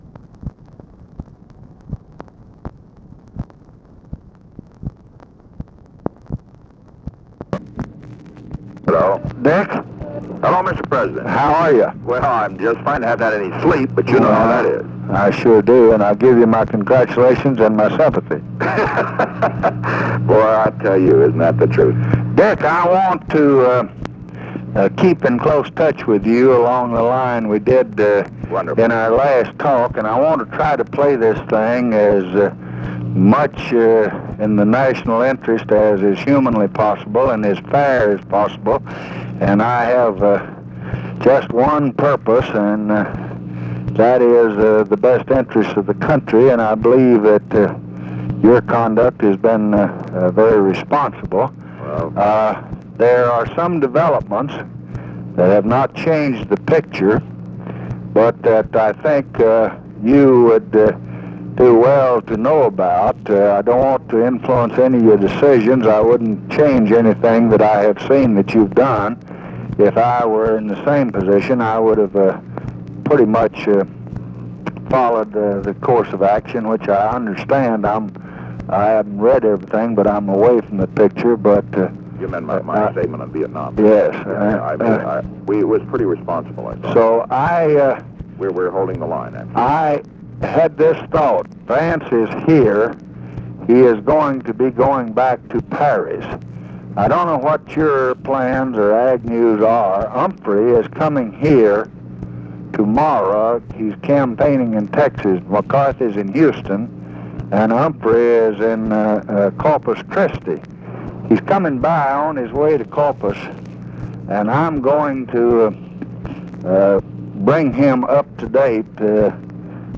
Conversation with RICHARD NIXON, August 8, 1968
Secret White House Tapes